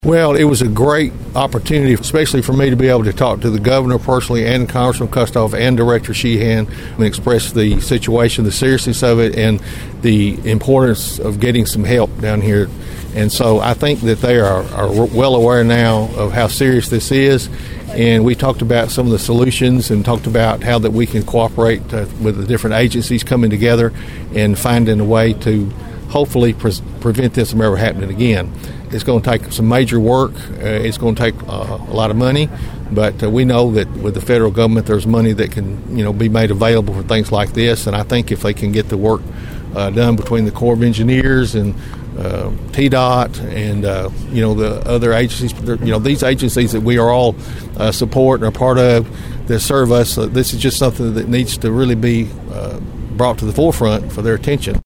Following the visit by Governor Bill Lee, TEMA Director Patrick Sheehan and Congressman David Kustoff, Mayor Carr told Thunderbolt News about the opportunity to request needed assistance for the residents living in a flood prone area.(AUDIO)